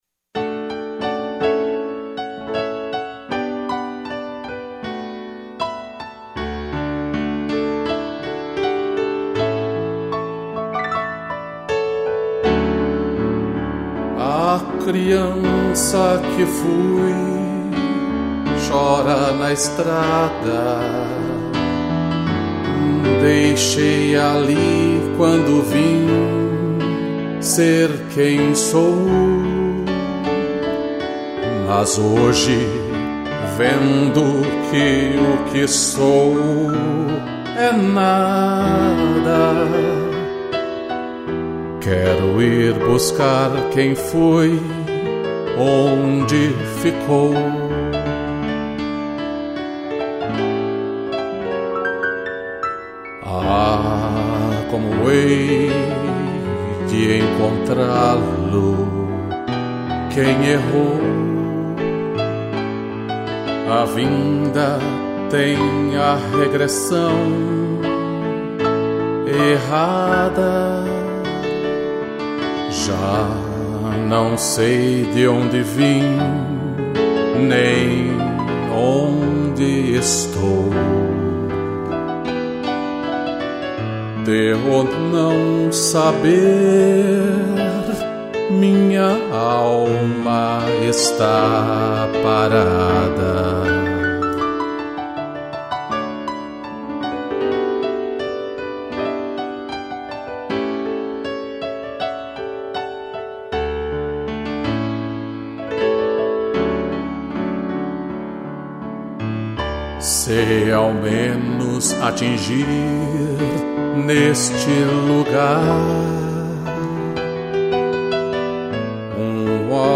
2 pianos